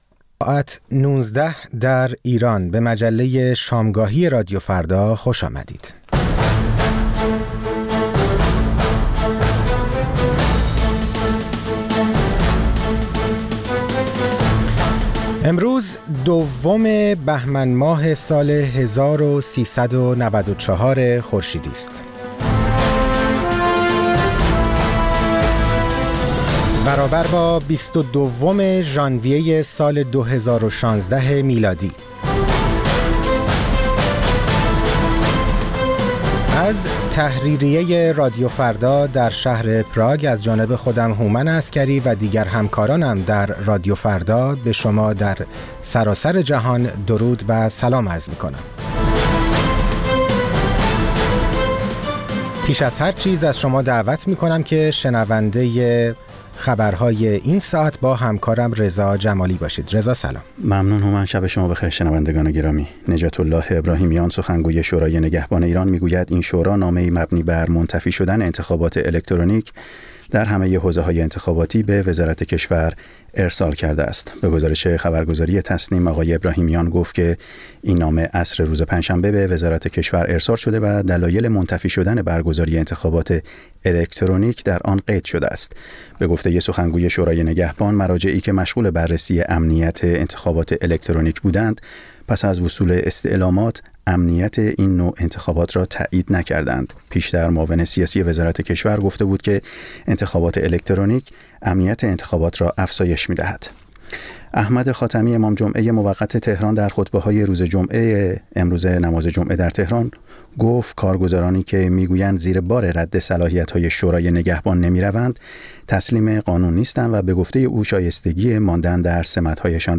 در نیم ساعت اول مجله شامگاهی رادیو فردا، آخرین خبرها و تازه‌ترین گزارش‌های تهیه‌کنندگان رادیو فردا پخش خواهد شد. در نیم ساعت دوم شنونده یکی از مجله‌های هفتگی رادیو فردا خواهید بود.